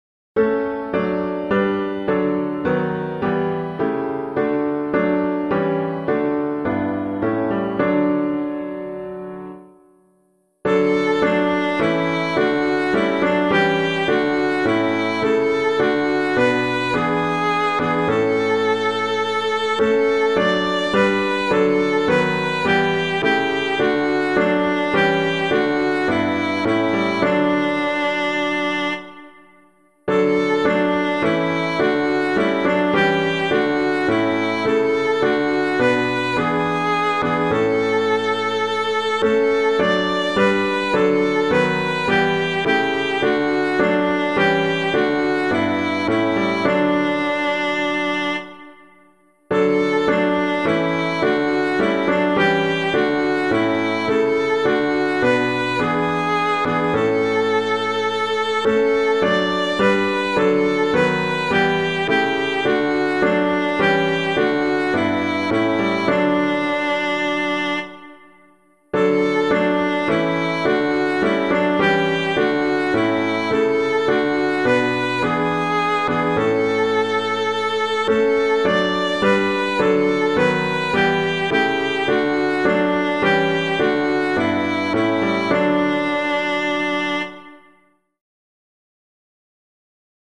piano
All You Who Seek a Comfort Sure [Caswall - SAINT BERNARD] - piano [mod].mp3